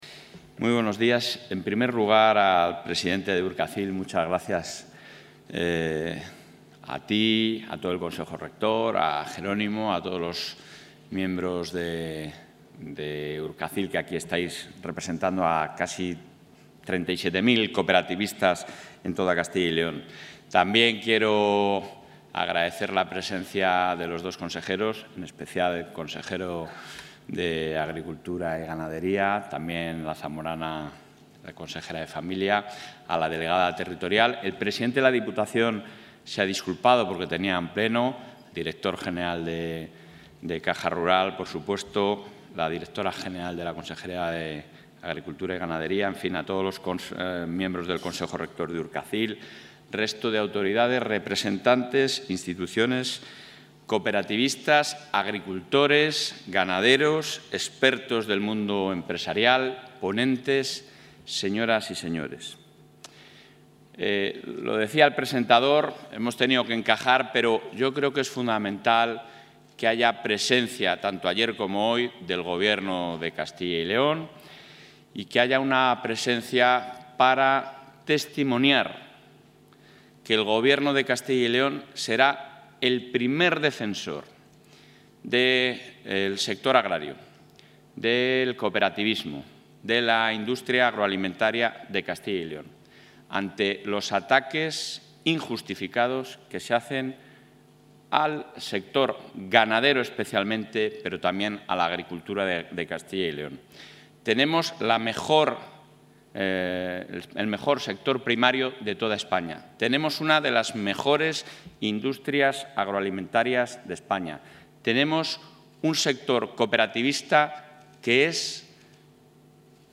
El presidente del Ejecutivo autonómico, Alfonso Fernández Mañueco, ha participado en el VI Congreso Regional de Cooperativas...
Intervención del presidente.